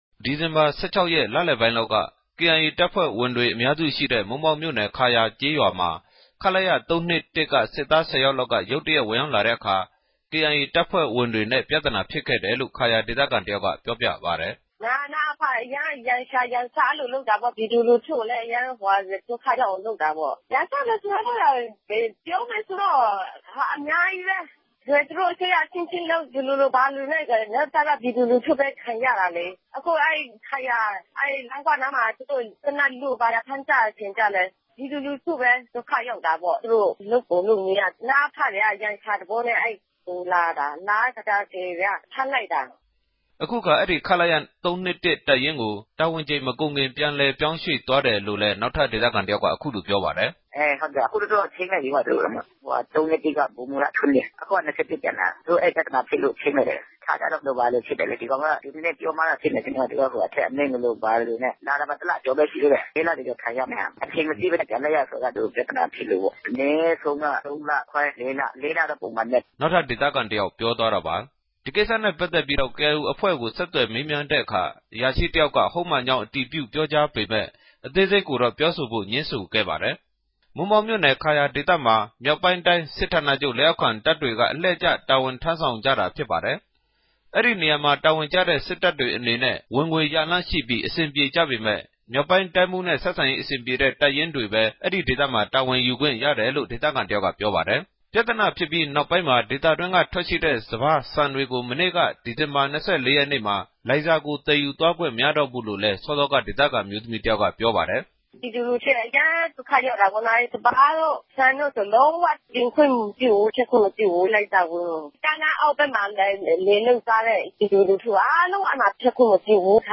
သတင်းပိုႚထားတာကို နားဆငိံိုင်ပၝတယ်။